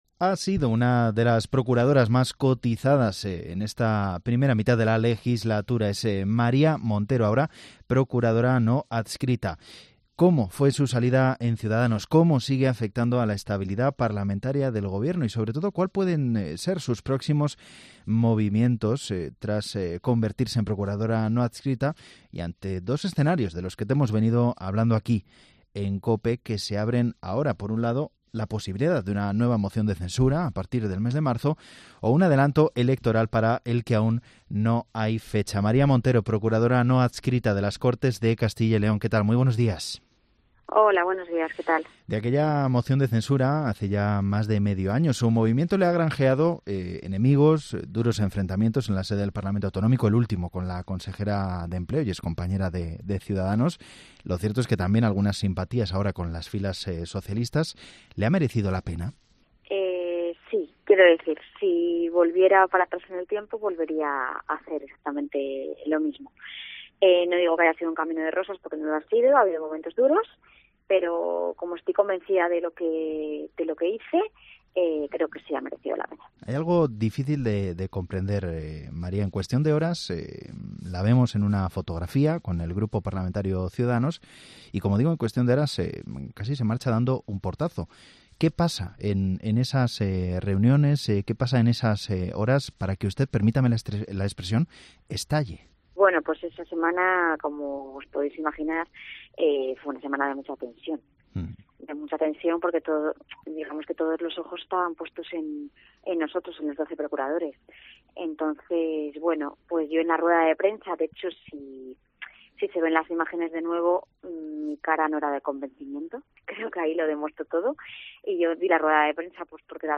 La fallida moción de censura del PSOE precipitó su salida de una formación cuyo funcionamiento en las Cortes, según ha descrito en Herrera en COPE Castilla y León, era “caótico” con “procuradores de primera y de segunda”.